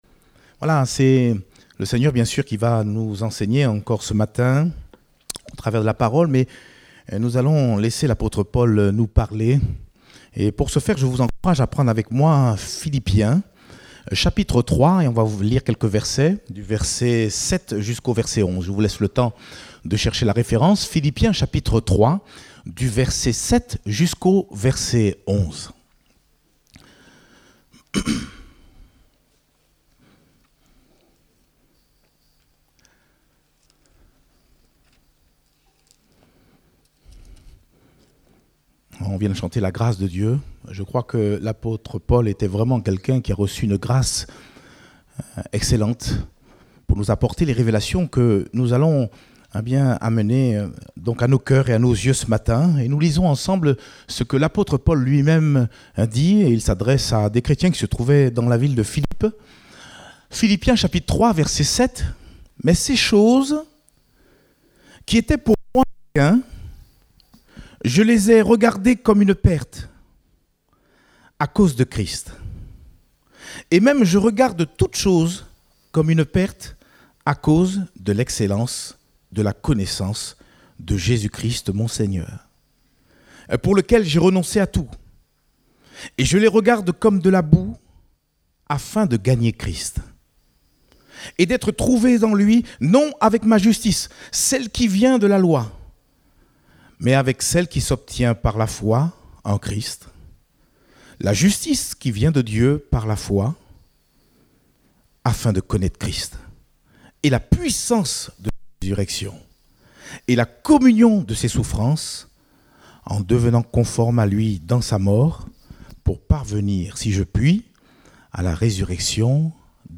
Date : 18 avril 2021 (Culte Dominical)